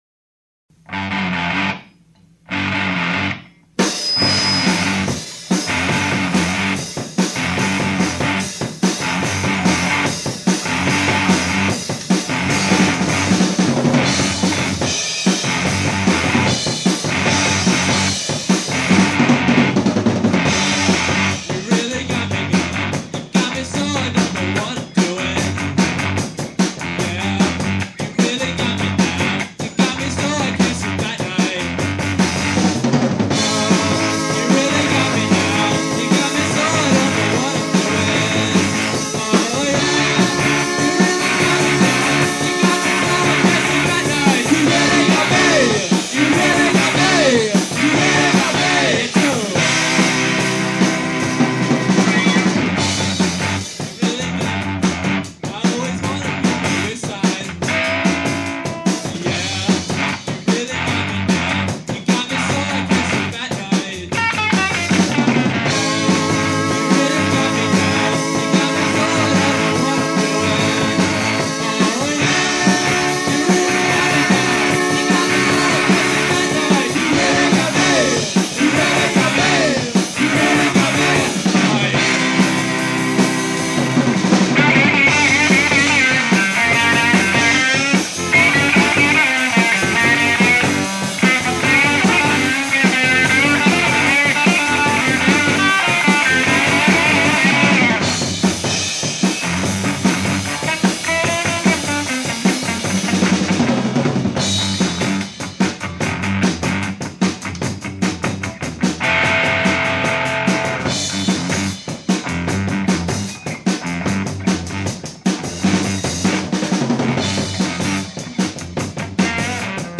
Click here to listen to the Tanglewood, PA version of the medley of Kinks' songs, Girl You Really Got Me, All Day and All Night, that the group rehearsed two weeks prior to a scheduled performance at the German House at 212 Warren Square, on Friday, April 2, 1982.